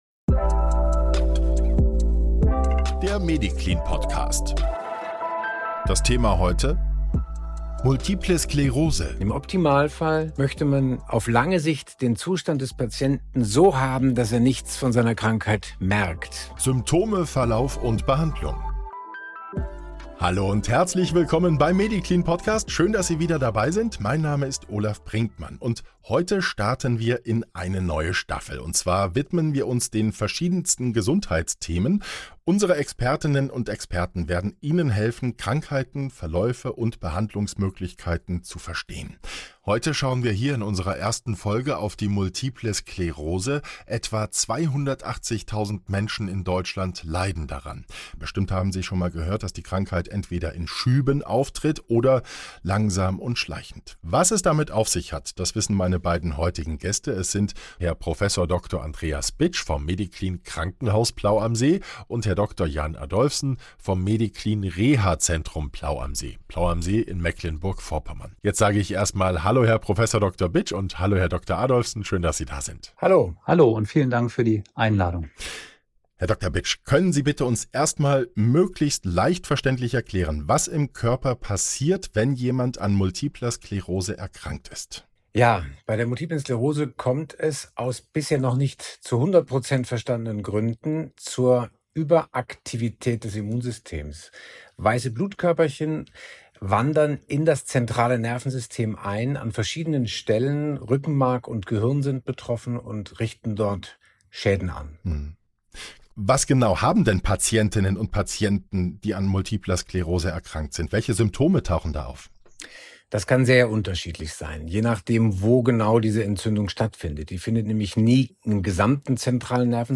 Welche Vorteile eine ambulante, stationäre und rehabilitative Behandlung an einem Standort für MS-Patient*innen hat, erklären zwei Mediziner des MEDICLIN Krankenhauses und Reha-Zentrums Plau am See in der ersten Folge unserer neuen Staffel "Ihr Gesundheitsratgeber".